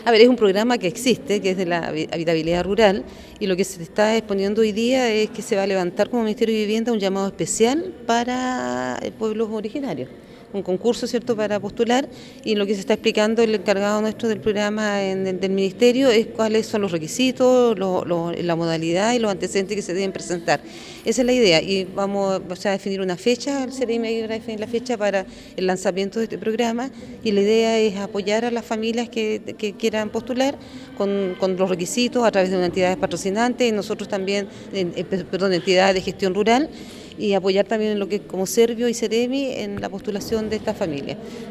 El programa está especialmente destinado a aquellas familias que forman parte del déficit habitacional, donde se abordaron los distintos antecedentes que se requieren para postular, según señalo Isabel de la Vega, Directora Regional del Servicio de Vivienda y Urbanismo.